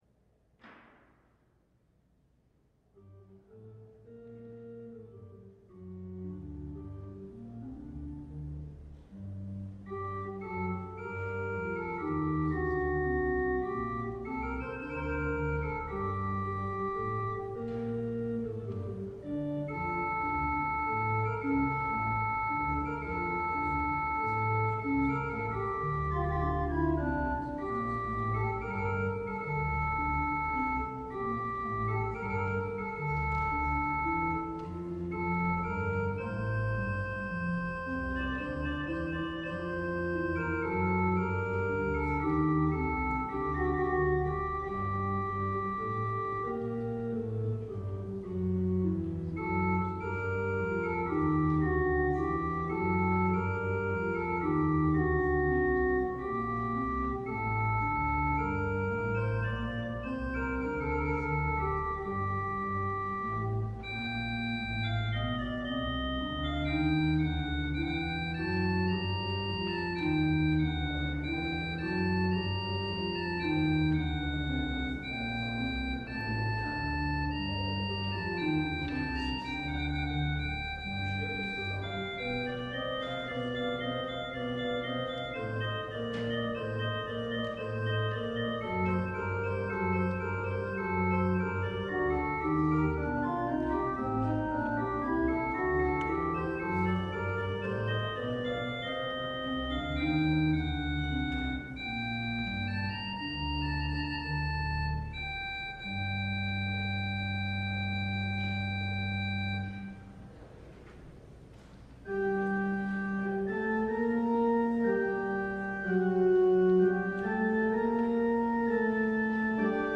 LIVE Morning Service - The Word in the Windows: Let Your Light Shine Before Others